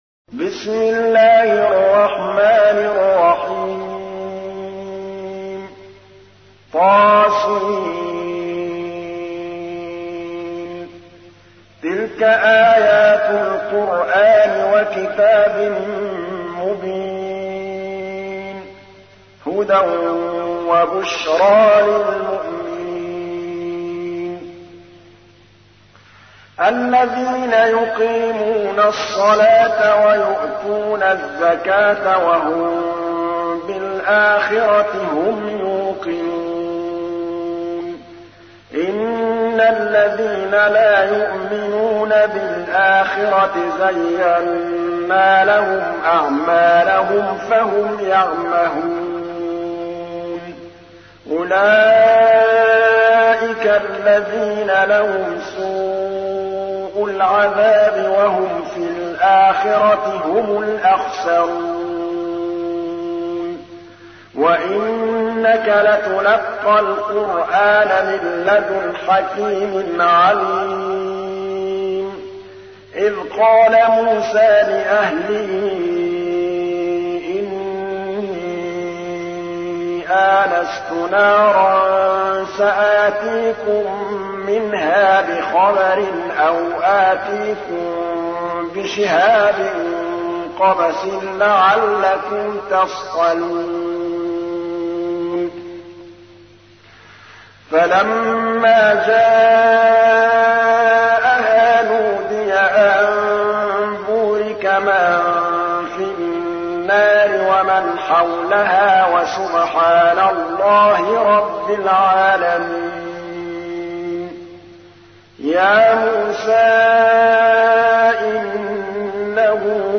تحميل : 27. سورة النمل / القارئ محمود الطبلاوي / القرآن الكريم / موقع يا حسين